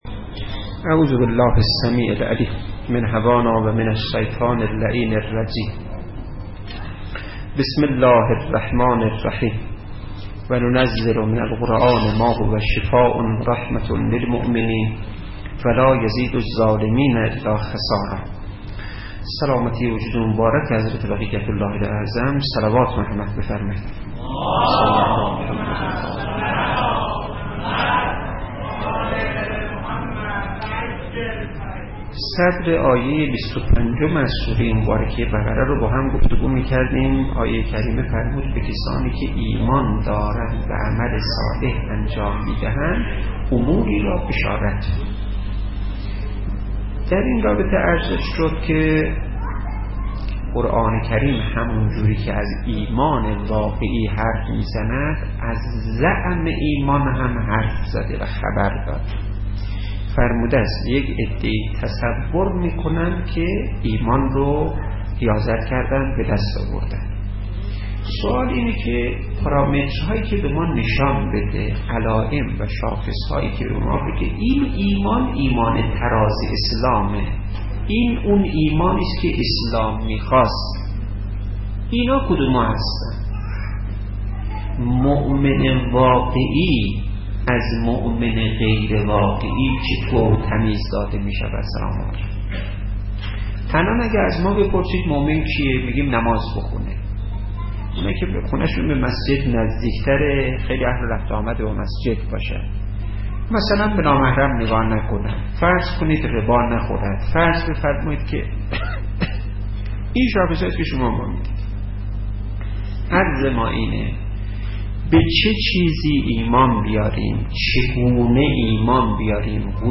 سخنرانی هایی در مورد ولایت فقیه